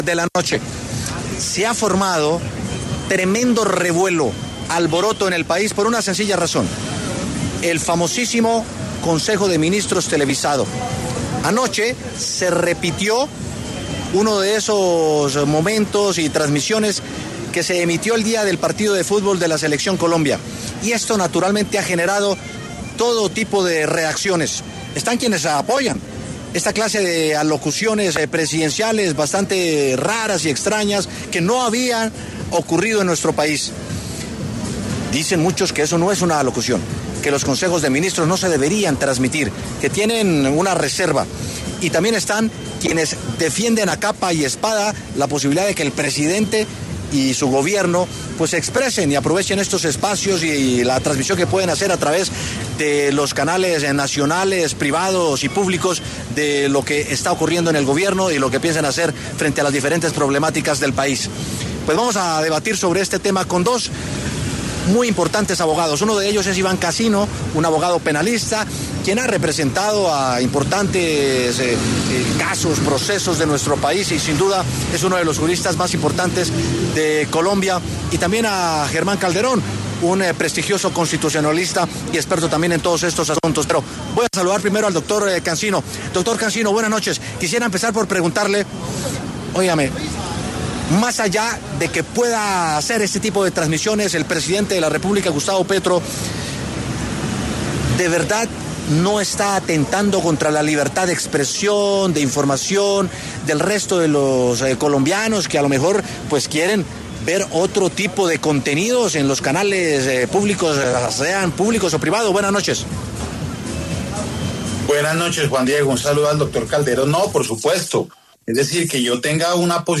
Debate: ¿Transmisión del consejo de ministros es un derecho ciudadano o un riesgo institucional?